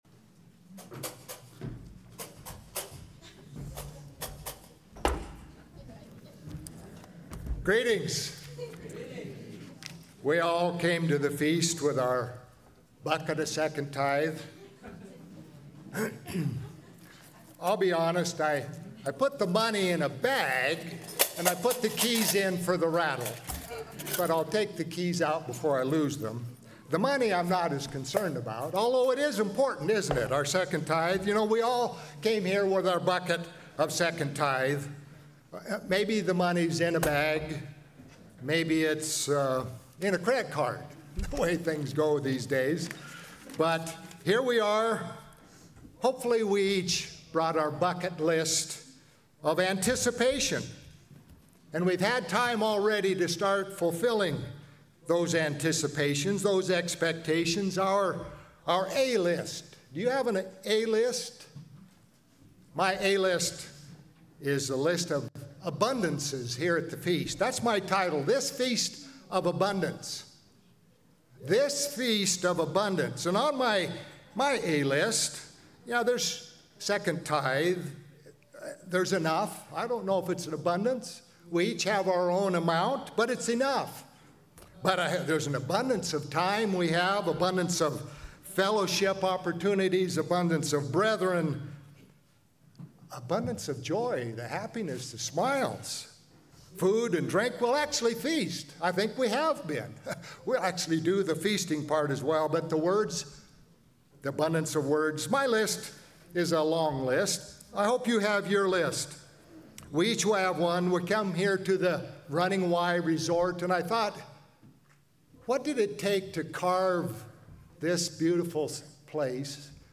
Split Sermon 1 - Day 2 - Feast of Tabernacles, Klamath Falls
This sermon was given at the Klamath Falls, Oregon 2024 Feast site.